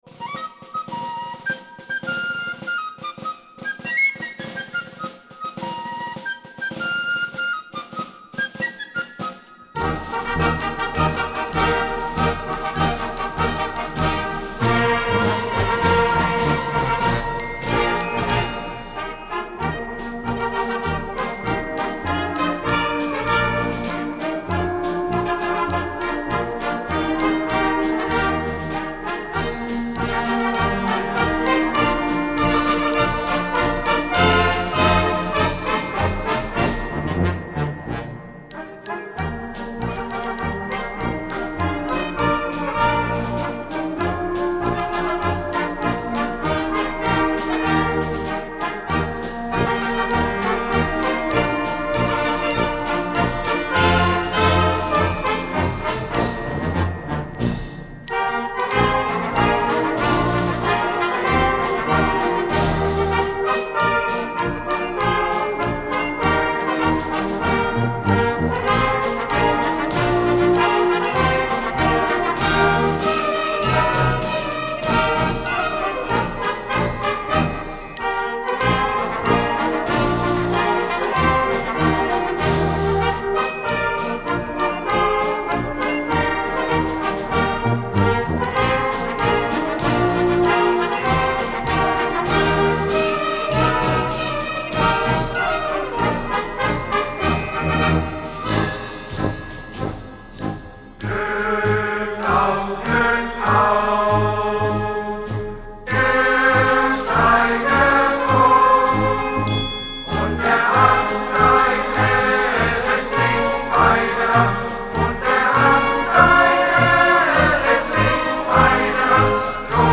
Bergmännische Lieder, Lieder über die Bergleute
Der Glückaufmarsch der Bergmannskapelle Stockheim.